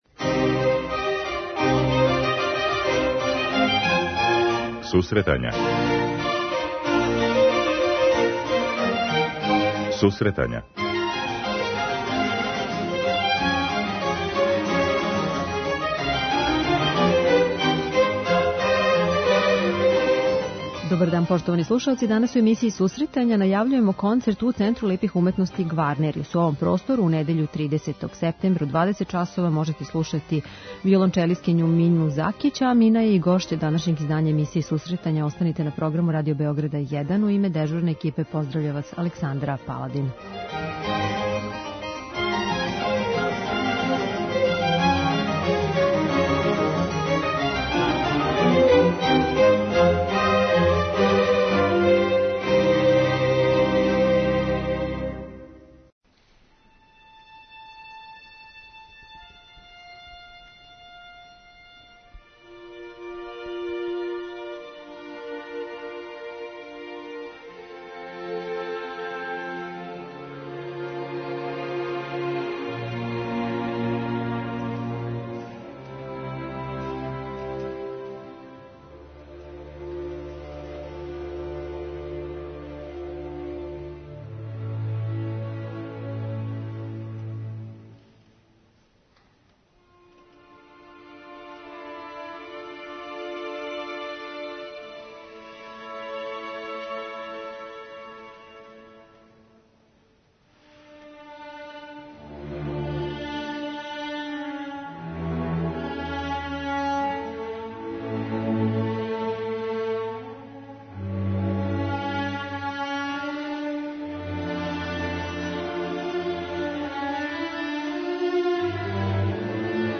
О програму, музици, професорима, концертима и будућности данас разговарамо са овом уметницом.